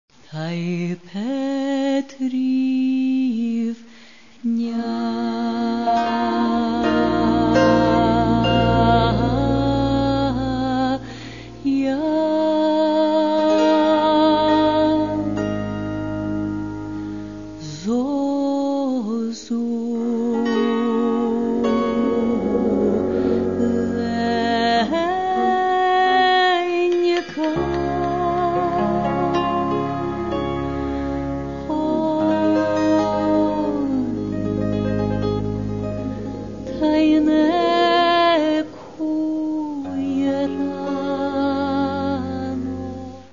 Каталог -> Народна -> Сучасні обробки
viola, violin, guitar, vocals
bandura, sopilka, vocals
overtone vocals